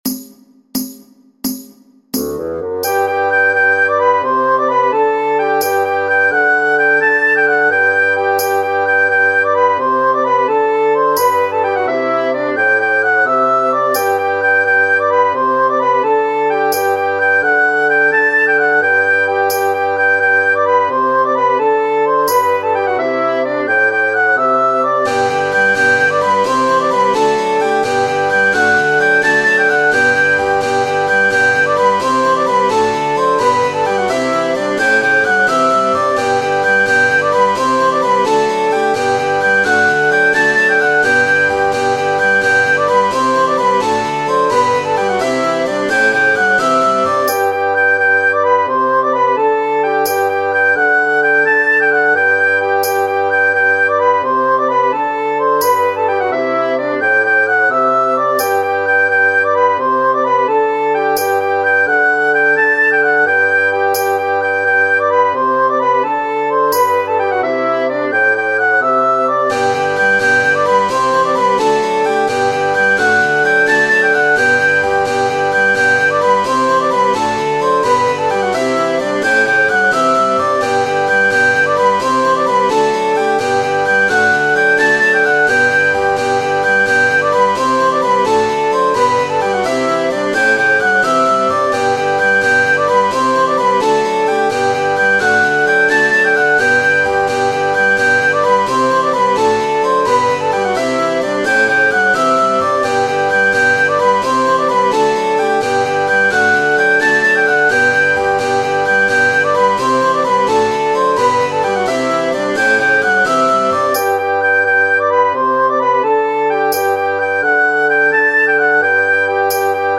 ”Diagonaalissa ulos keskustasta” -tanssi. 4 parin neliössä tanssittava ECD.
Yksi koreografian kohta kestää musiikissa 8 iskua.